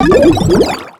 Cri de Symbios dans Pokémon X et Y.